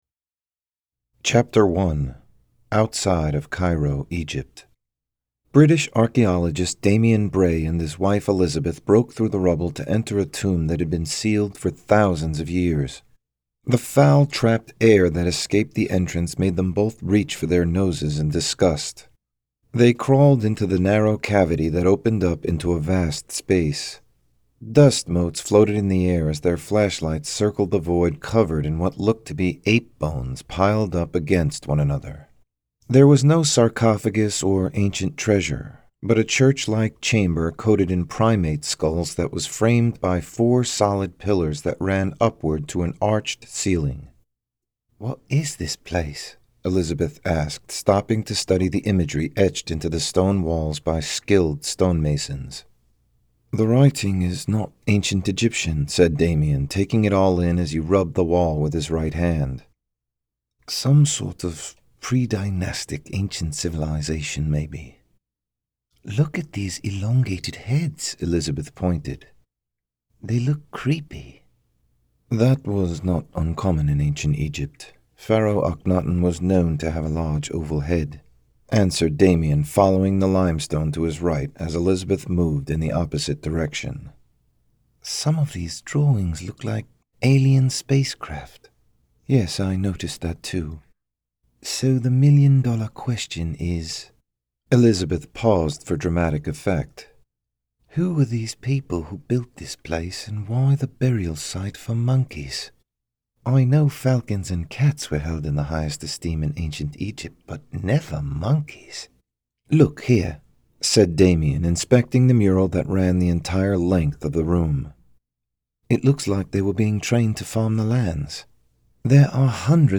Guardians Of Egypt Short Story Audio Book Chapter 1